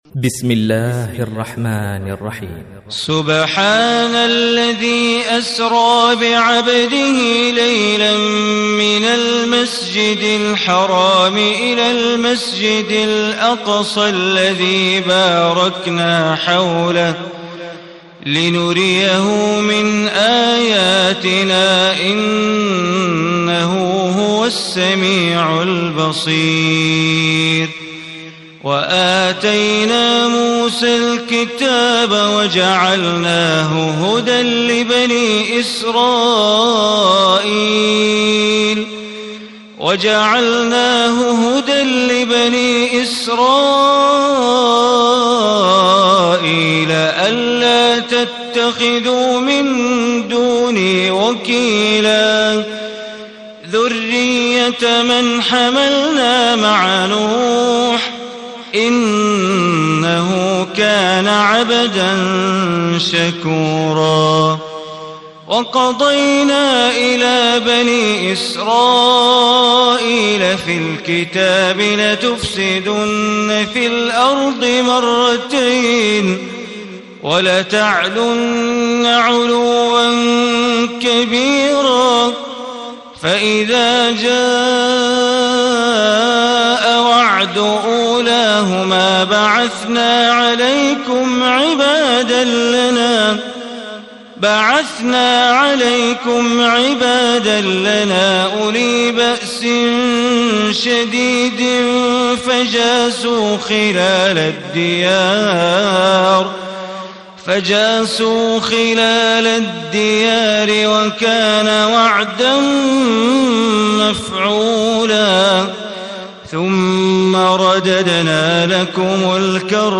Surah Isra Mp3 Recitation by Bandar Baleela
Surah Isra, listen or play online mp3 recitation in the voice of Sheikh Bandar Baleela.